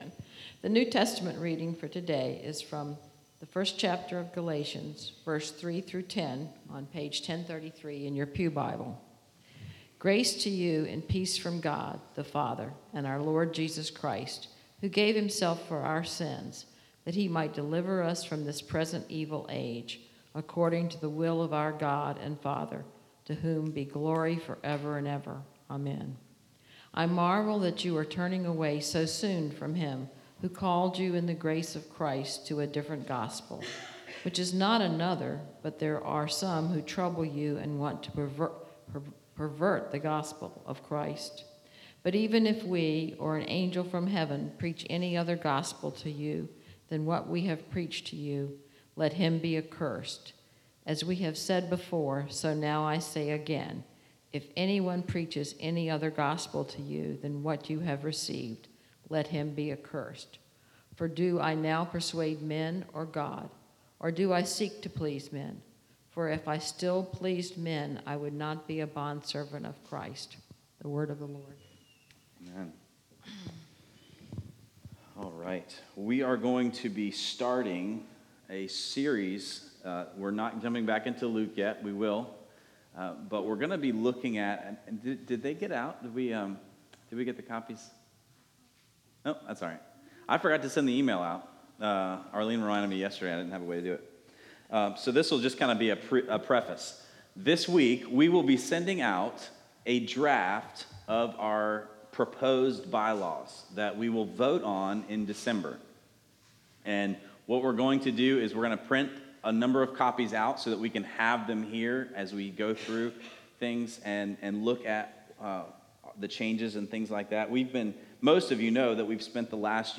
This week begins a Sermon series that will take us through the fall.